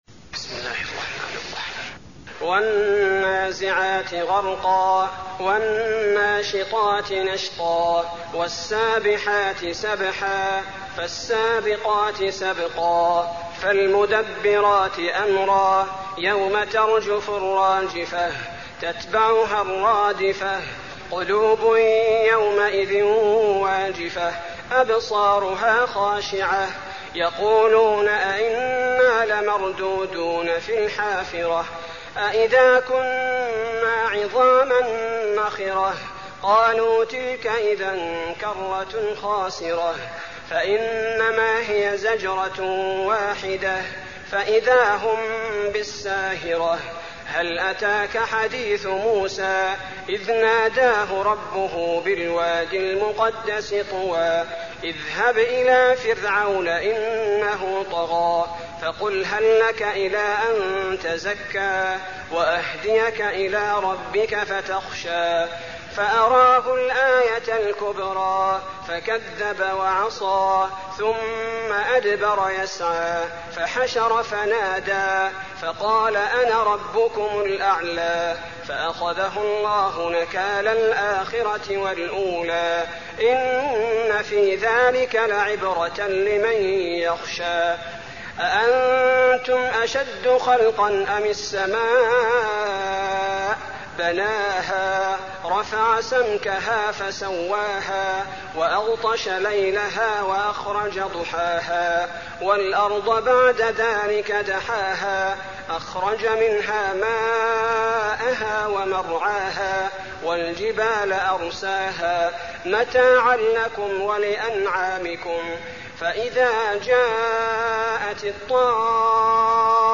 المكان: المسجد النبوي النازعات